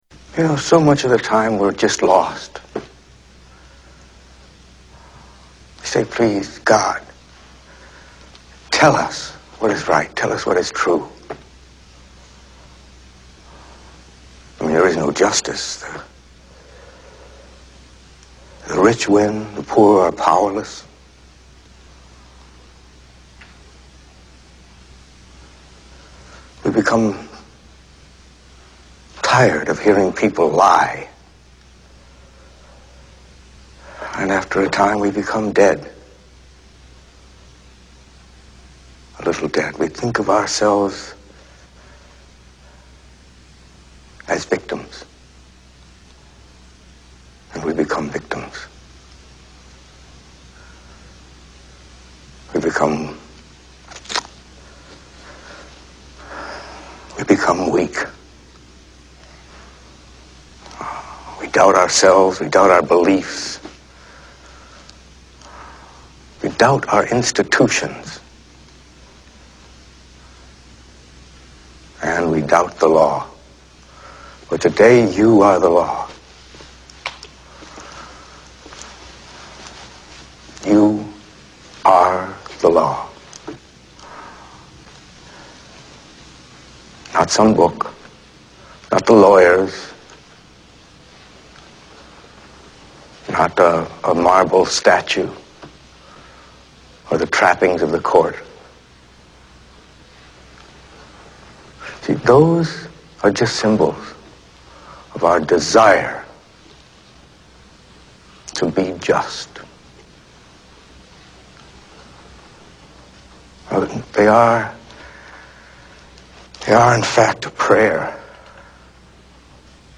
Tags: Greatest Movie Monologues Best Movie Monologues Movie Monologues Monologues Movie Monologue